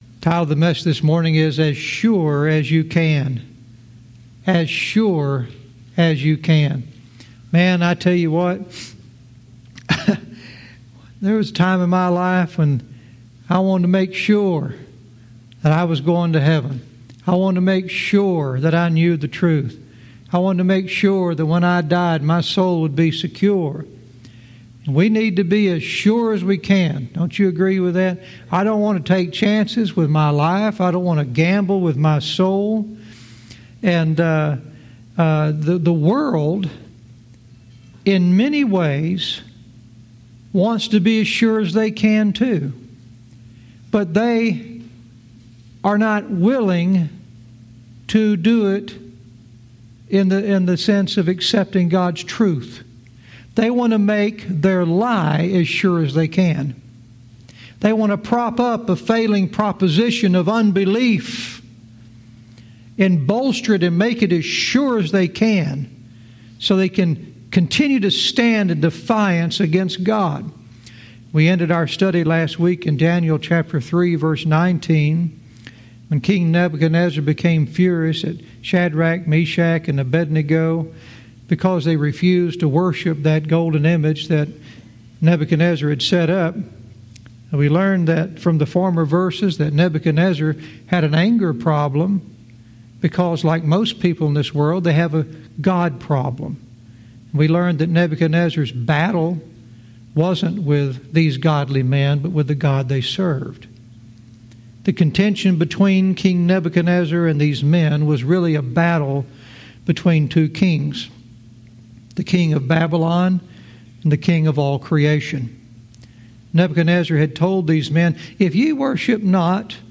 Verse by verse teaching - Daniel 3:19-21 "As Sure As You Can"